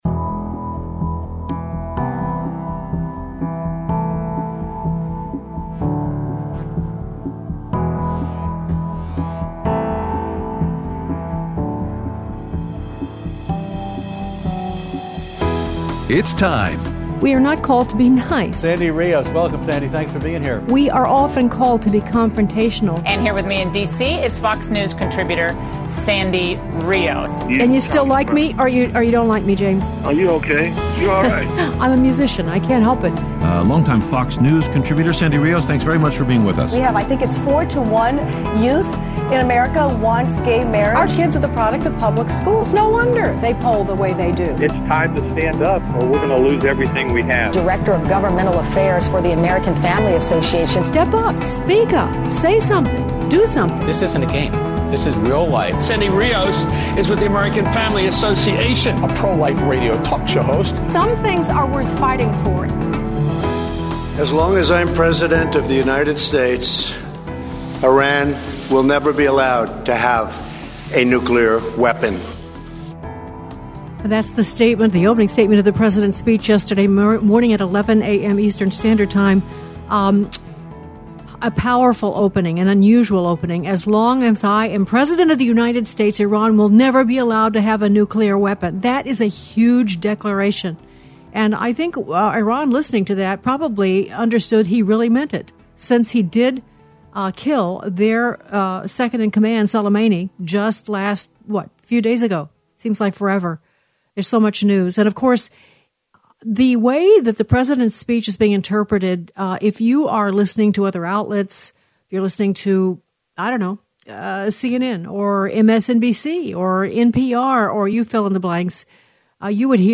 President Trump's Speech After Iran Attacked Military Bases, War Powers Act Fight, and Your Phone Calls
Aired Thursday 1/9/20 on AFR 7:05AM - 8:00AM CST